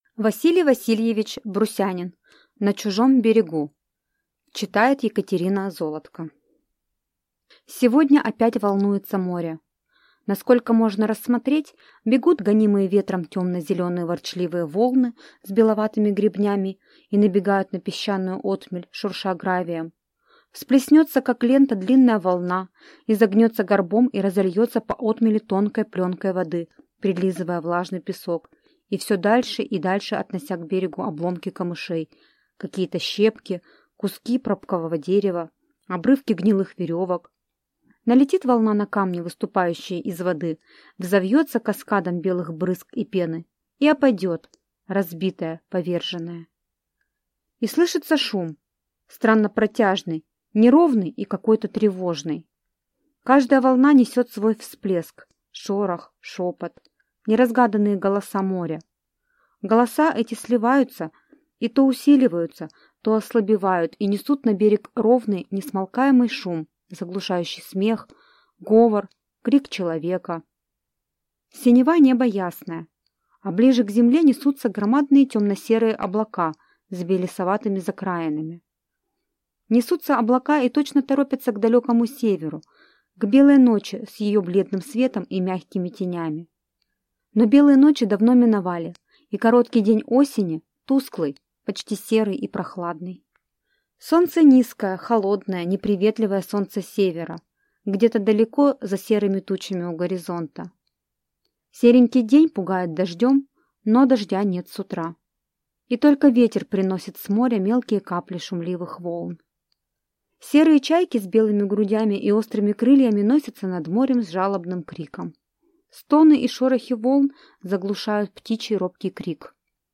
Аудиокнига На чужом берегу | Библиотека аудиокниг
Aудиокнига На чужом берегу